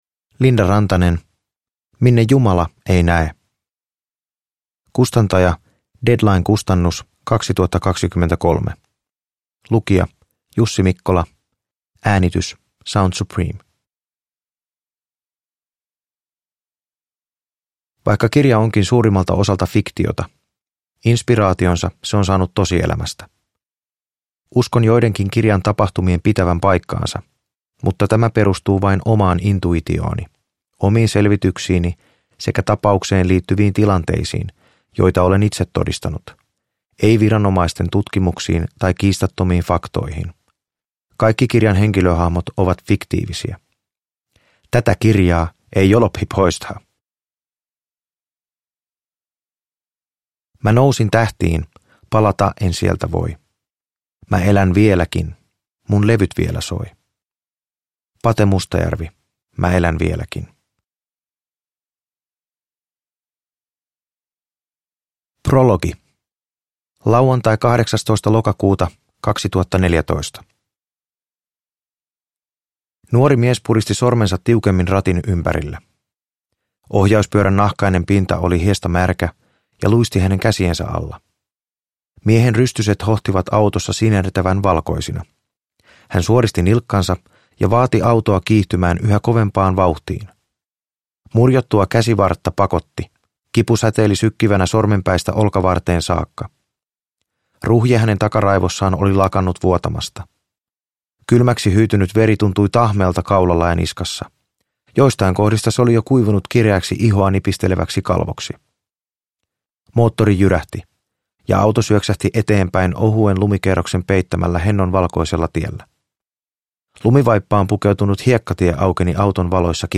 Minne Jumala ei näe – Ljudbok – Laddas ner